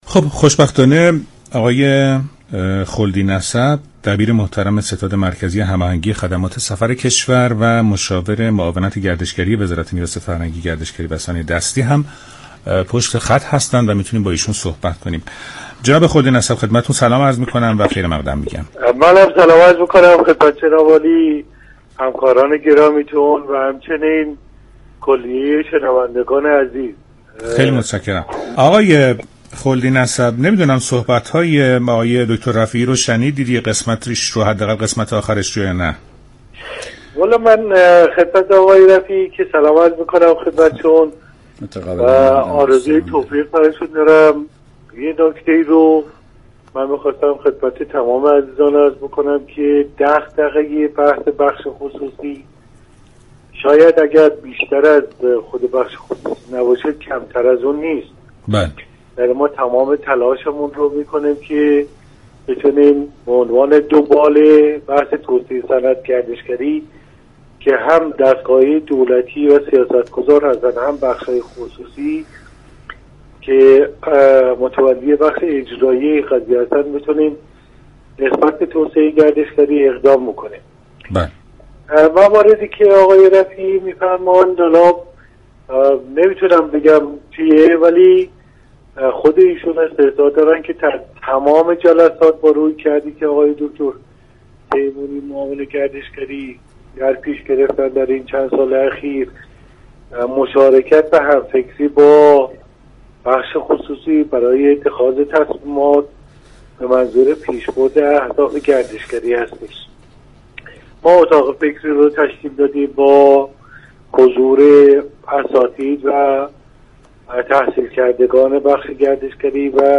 برنامه ایران امروز شنبه تا سه شنبه هر هفته 12:40 از رادیو ایران پخش می شود.